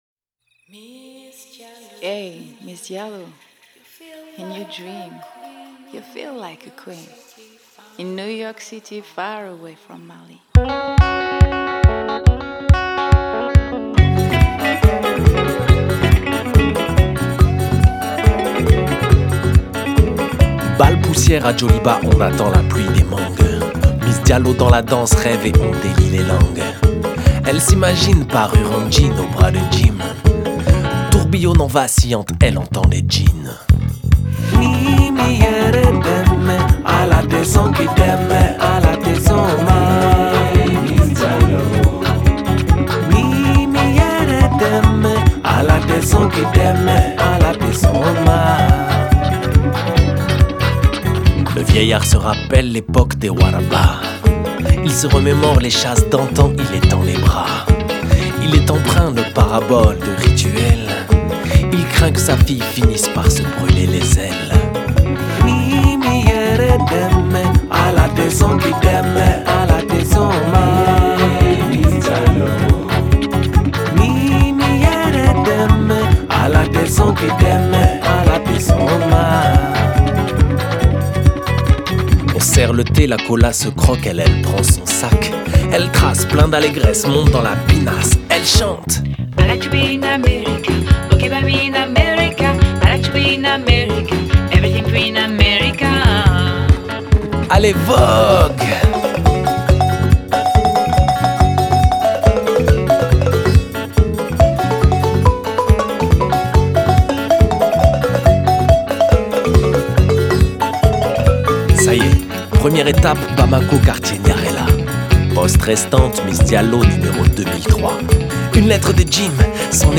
Genre: World, Folk